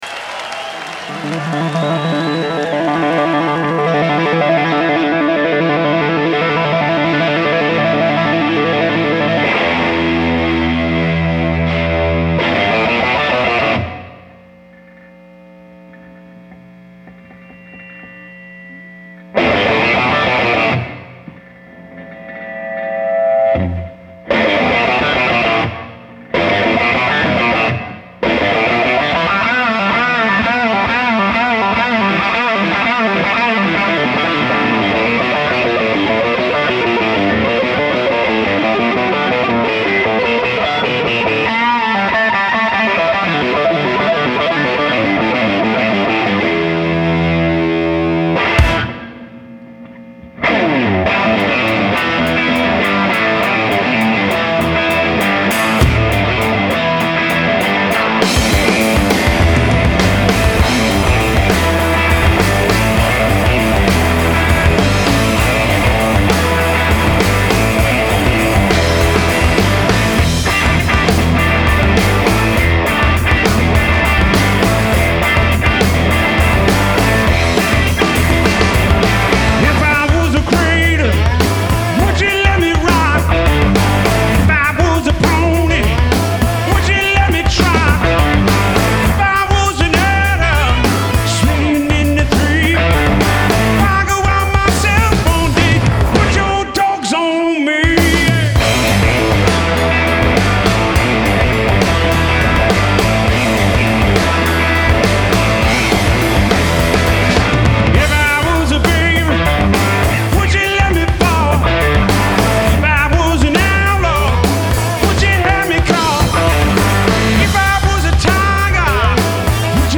Genre : Rock, Blues